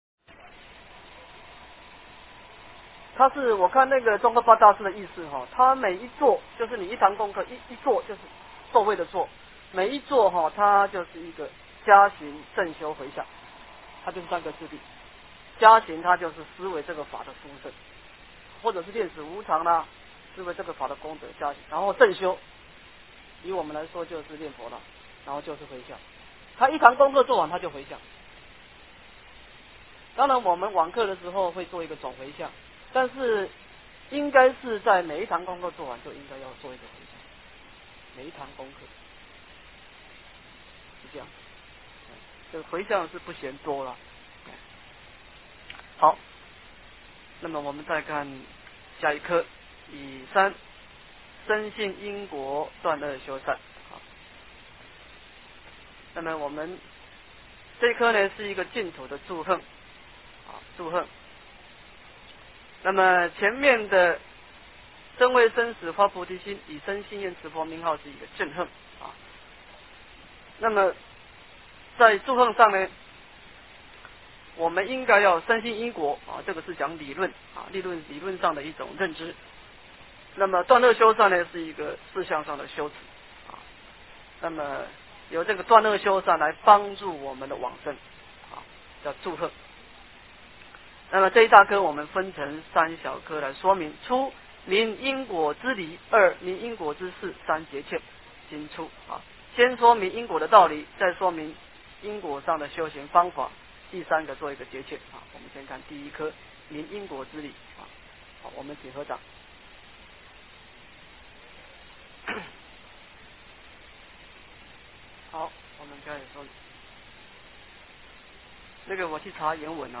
印光法师文钞16 - 诵经 - 云佛论坛